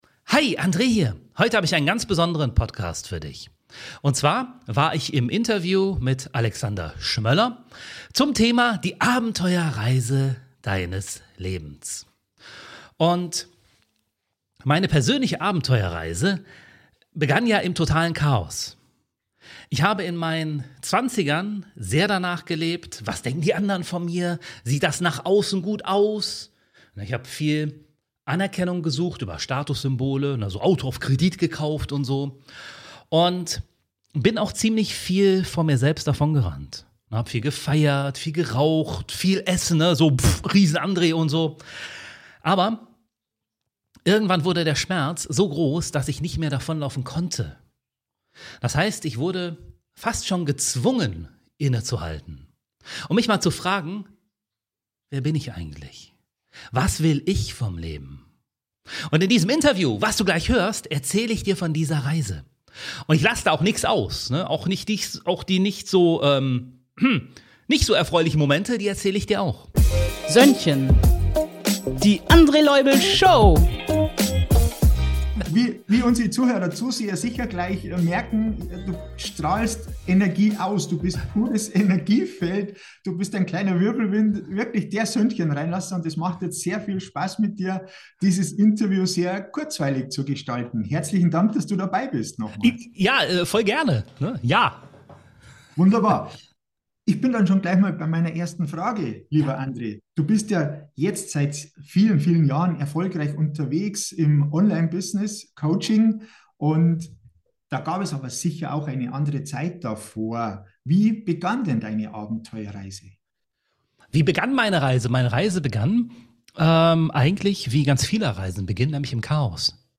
In diesem Interview erzähle ich Dir von dieser Reise - und lasse auch nix aus.